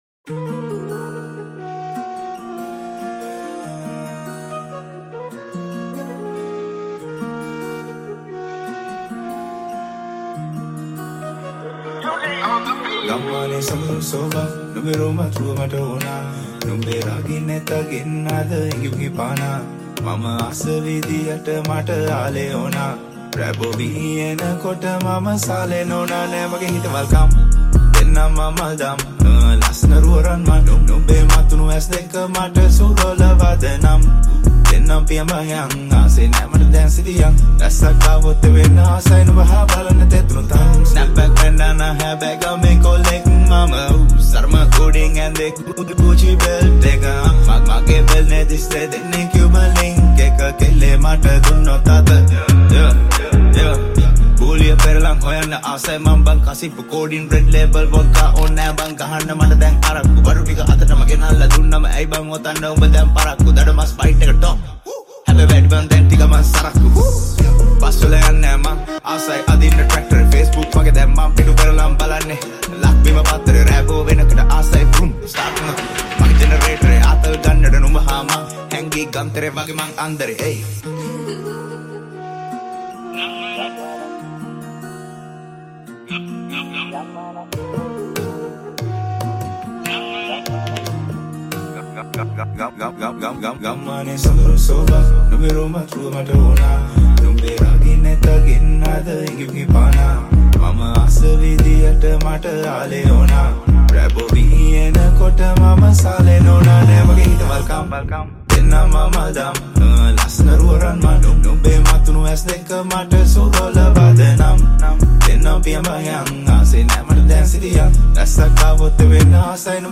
High quality Sri Lankan remix MP3 (9.3).
Rap